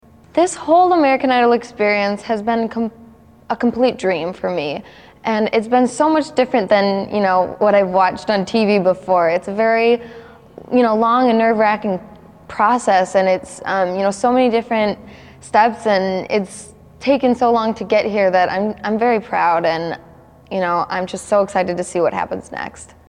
Category: Television   Right: Personal